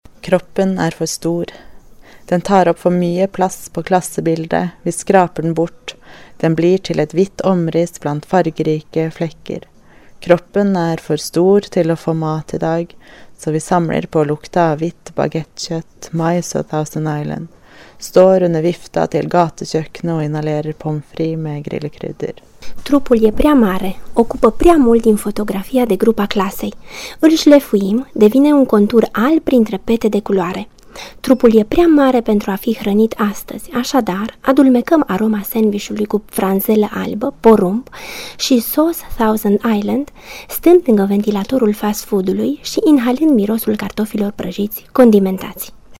poem-norvegiana.mp3